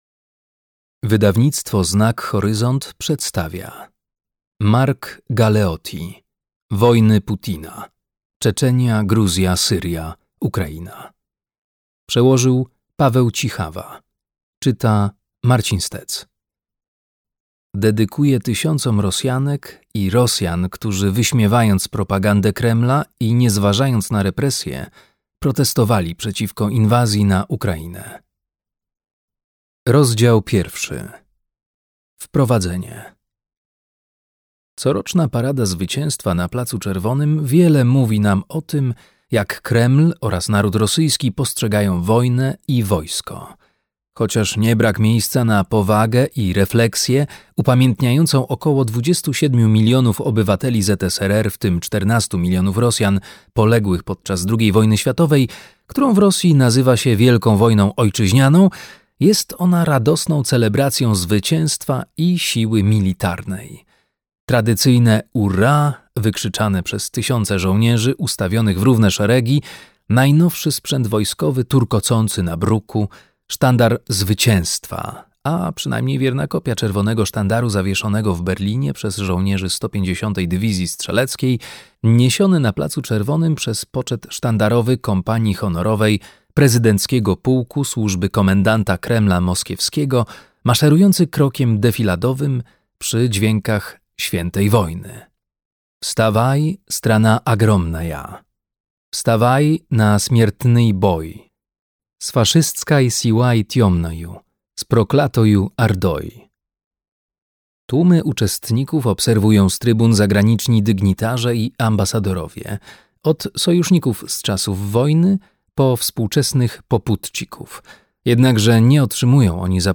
Czeczenia, Gruzja, Syria, Ukraina - Mark Galeotti - audiobook - Legimi online